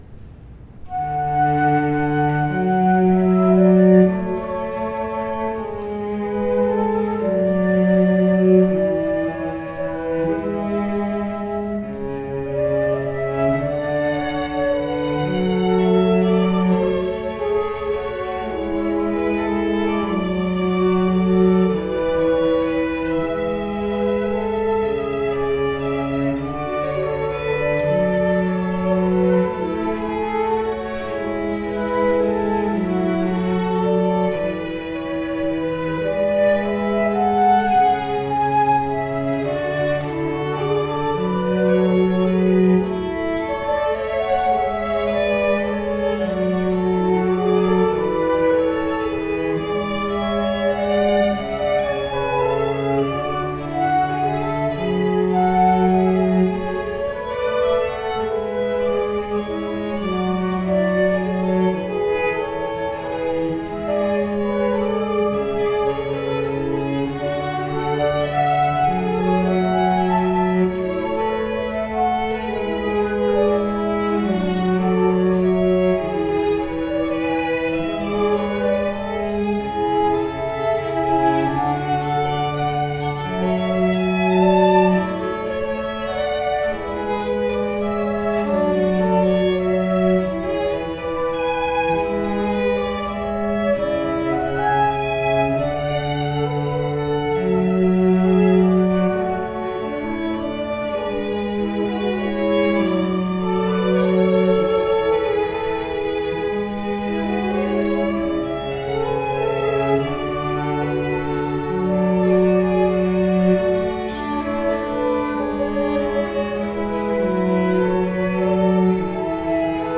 It was a pleasure to play for such a nice audience.